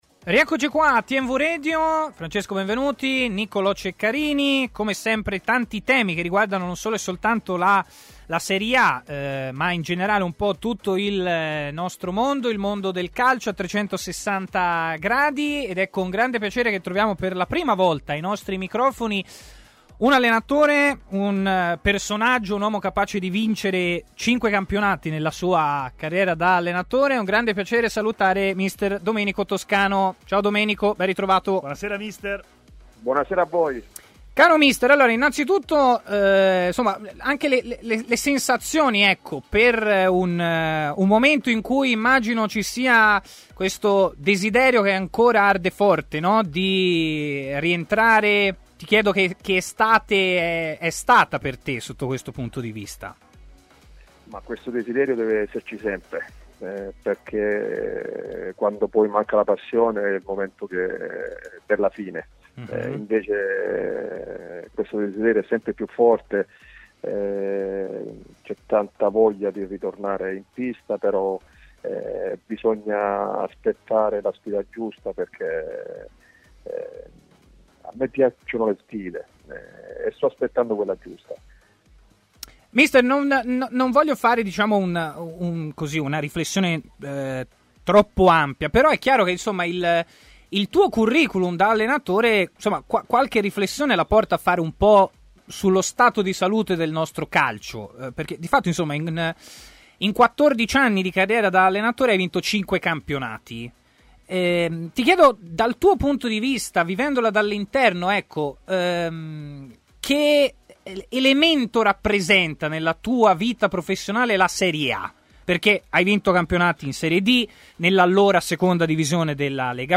Stadio Aperto, trasmissione di TMW Radio